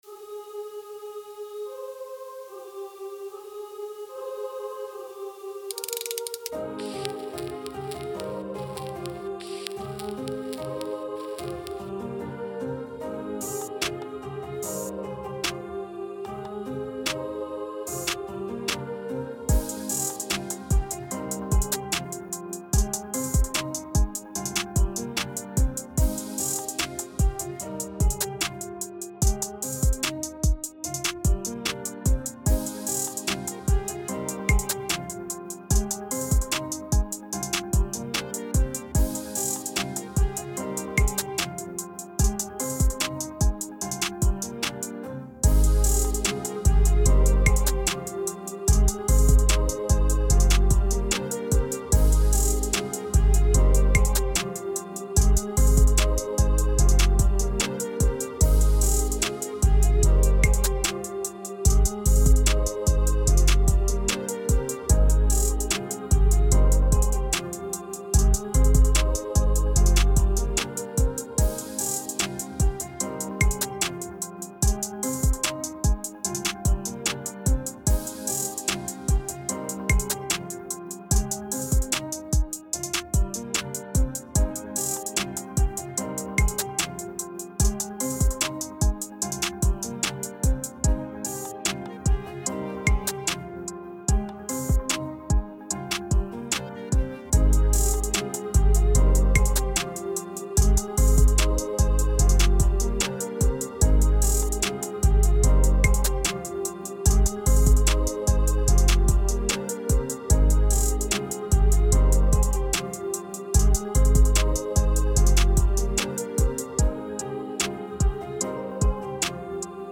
Hip-hop Эмоциональный 148 BPM